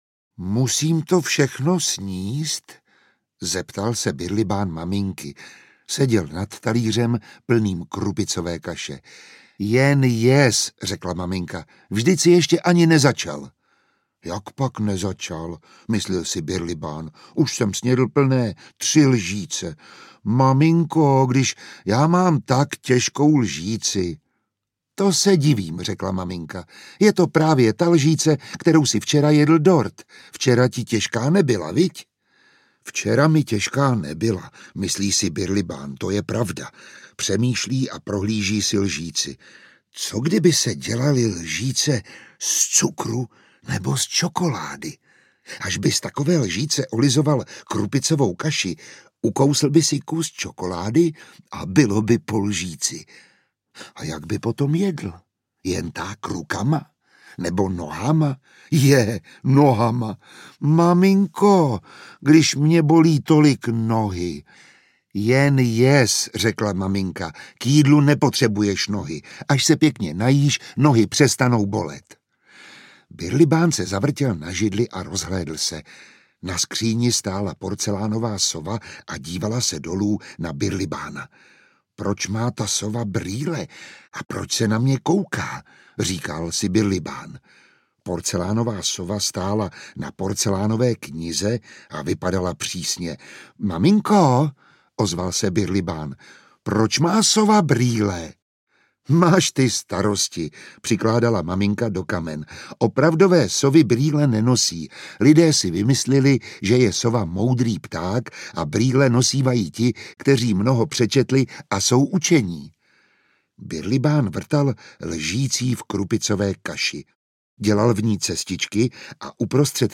Birlibán audiokniha
Ukázka z knihy
• InterpretMiroslav Táborský